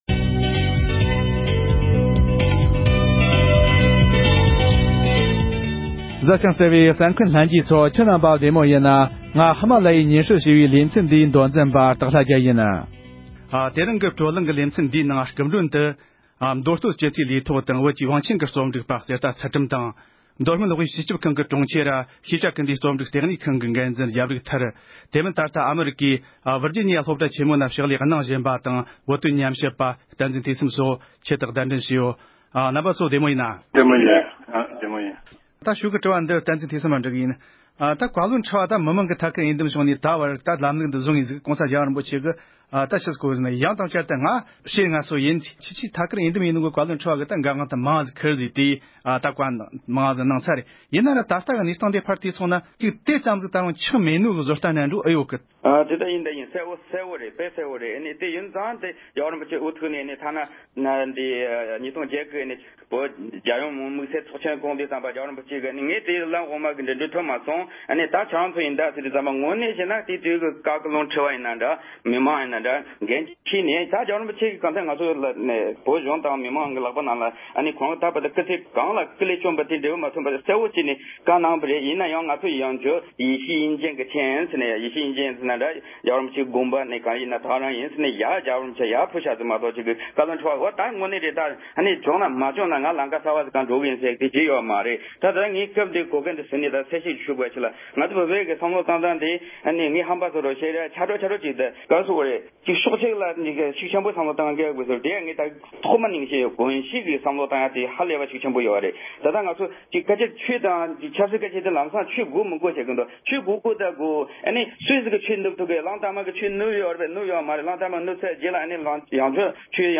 ད་ལྟ་བཀའ་བློན་ཁྲི་པའི་འོས་མི་ནང་ཞུགས་མཁན་རྣམས་ཀྱིས་བློ་སྟོབས་དང་འབྲེལ་མ་འོངས་པའི་ཐུགས་འགན་བཞེས་ཕྱོགས་ཐད་བགྲོ་གླེང༌།